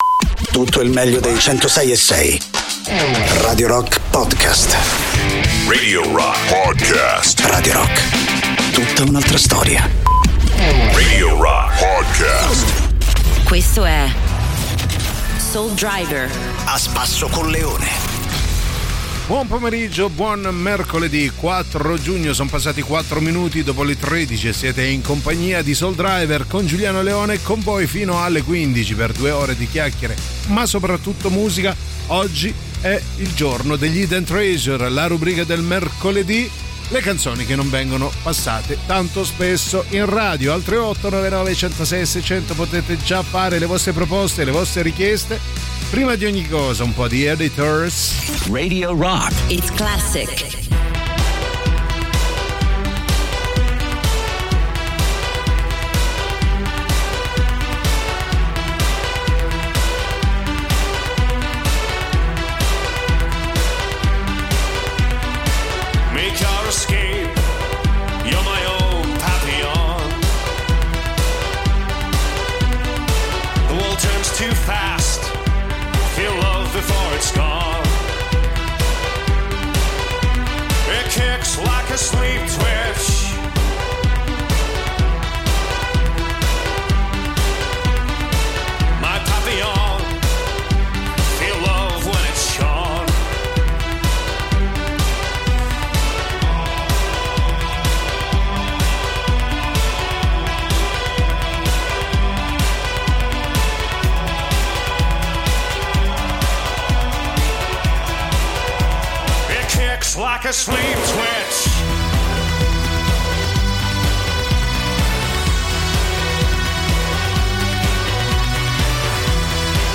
in diretta dal lunedì al venerdì, dalle 13 alle 15